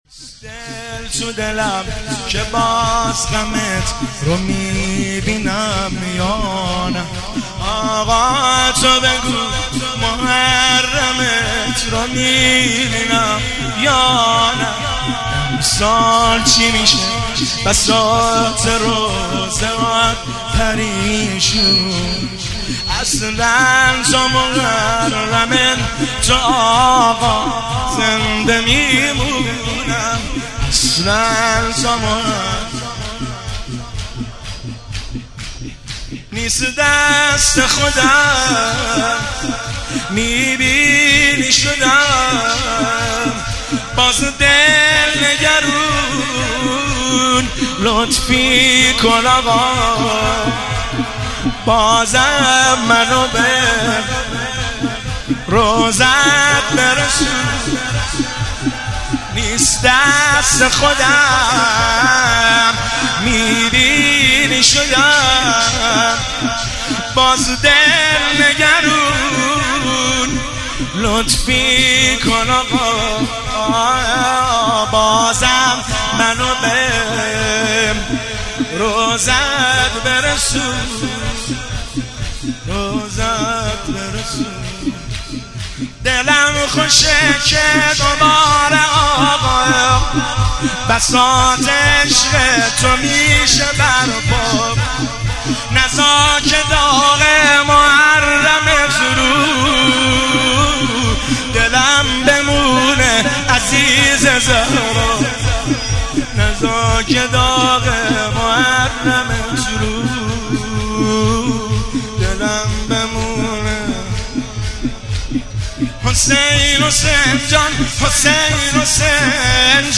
دانلود مداحی جدید